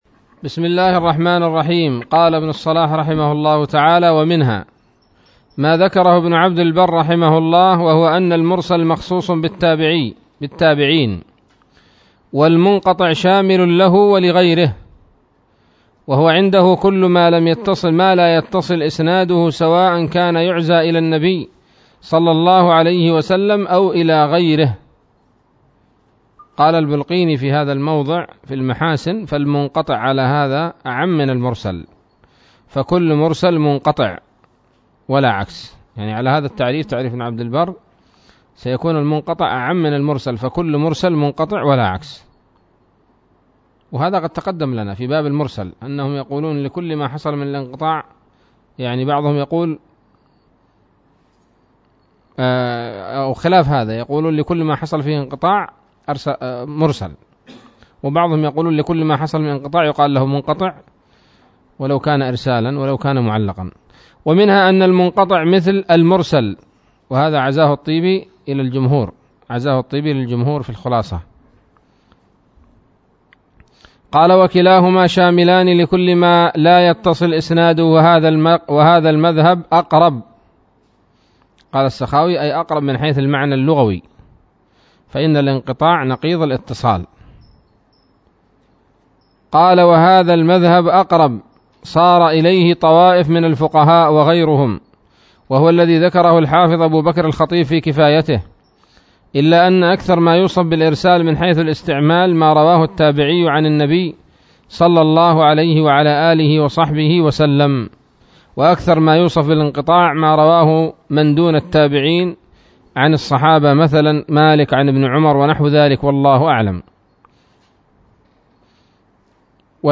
الدرس السادس والعشرون من مقدمة ابن الصلاح رحمه الله تعالى